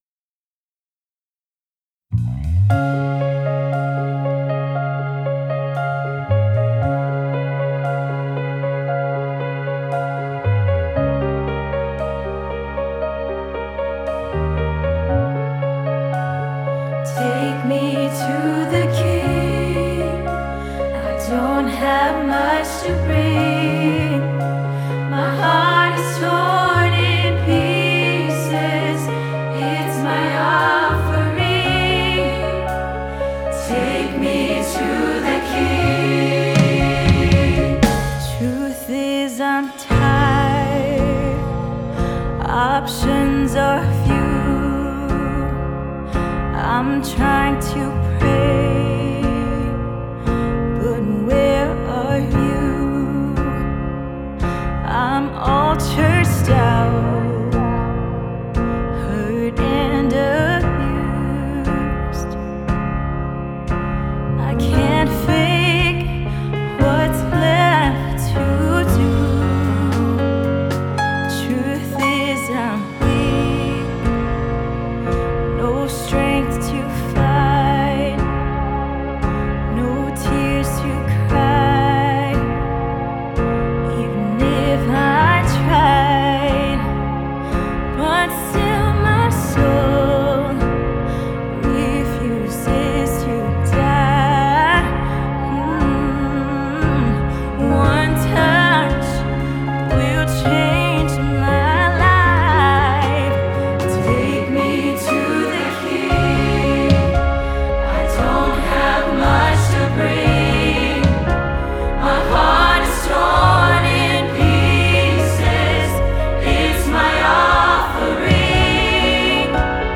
This audio was recorded from the 2020 Wheaton School.